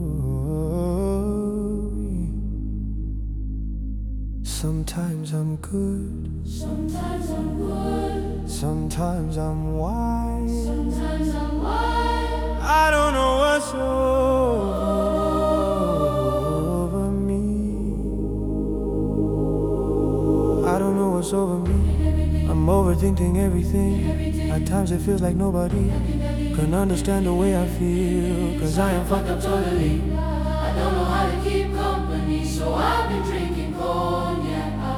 Жанр: Поп музыка
Afro-Pop, African